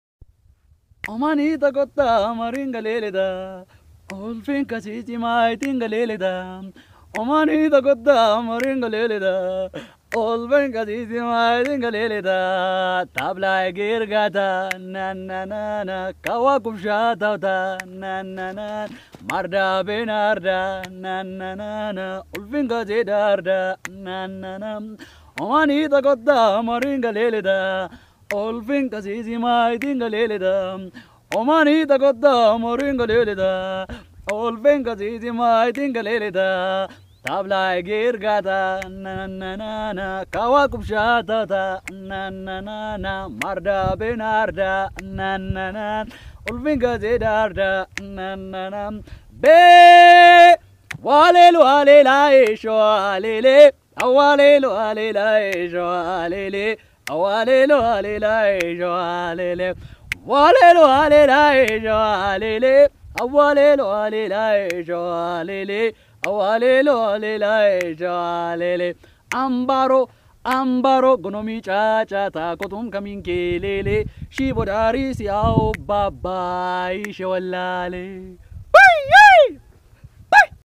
kunama music Cover